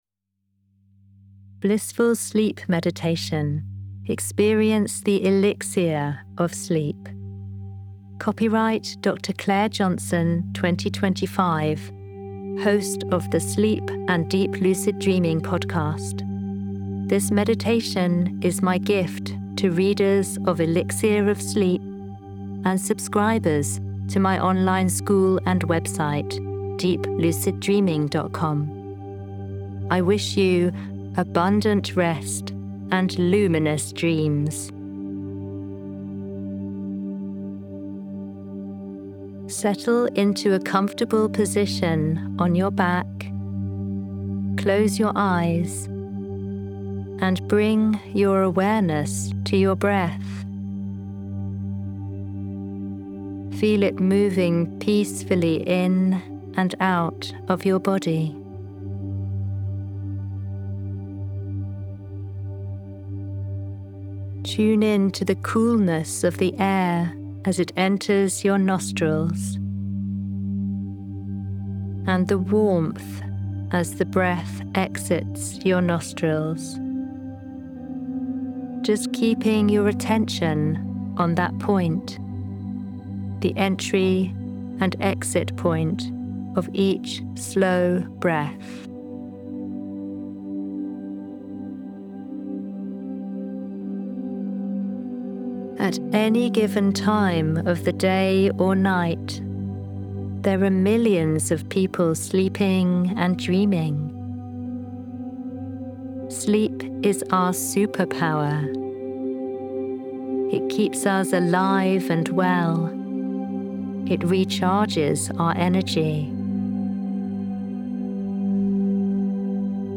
BONUS: Blissful Sleep Meditation 40 Minutes with Brain-cooling, Heart-breathing, Worry Release...